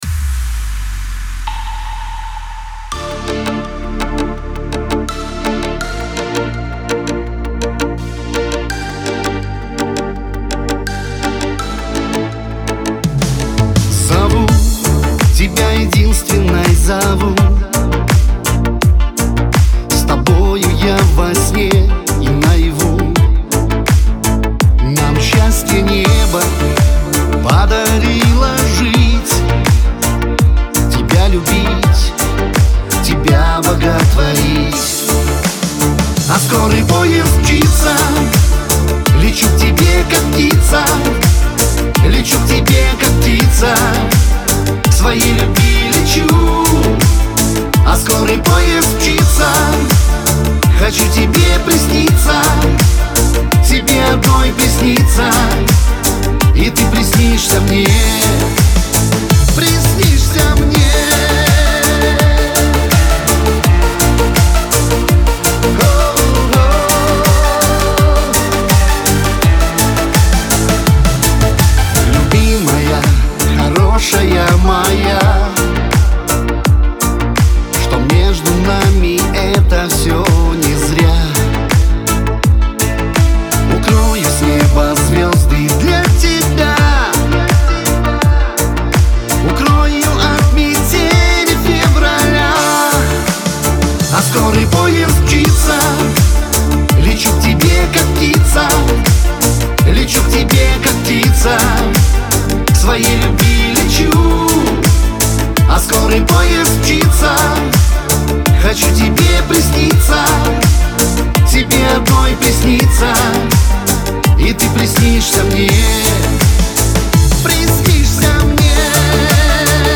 диско , pop , эстрада